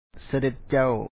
Pronunciation Notes 20
sadét càw Prince